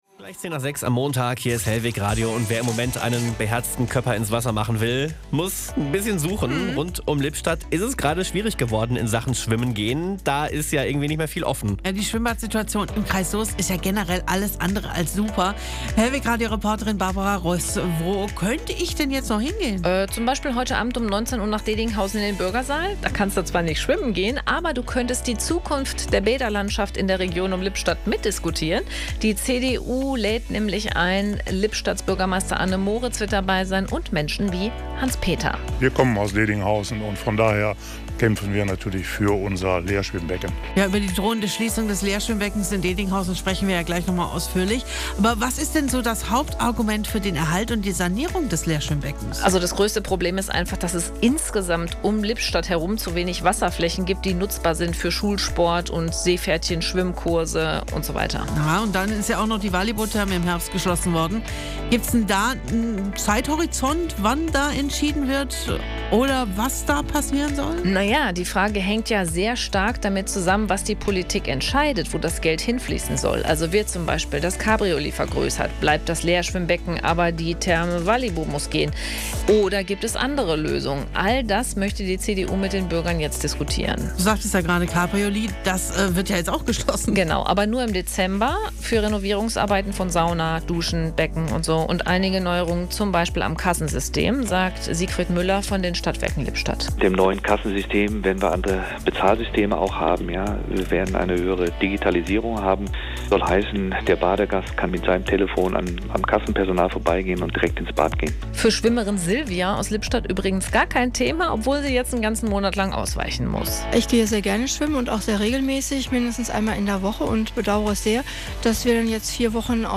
HR-Hörer*innen im Ton